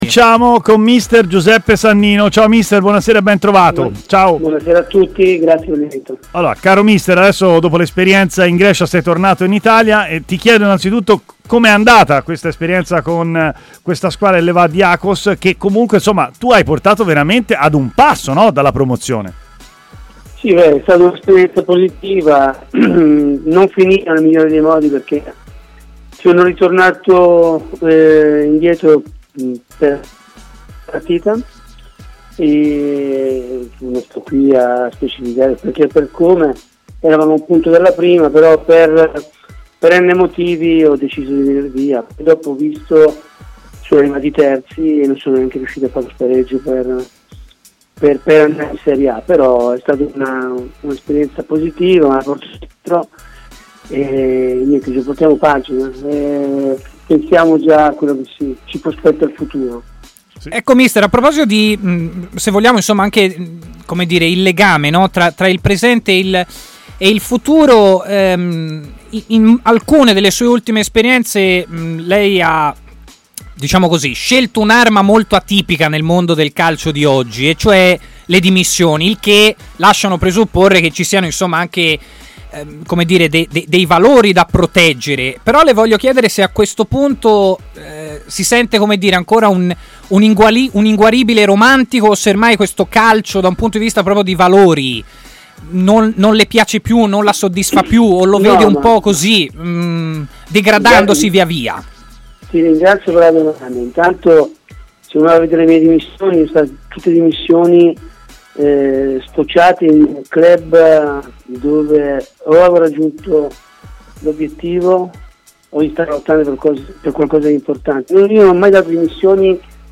ha parlato in diretta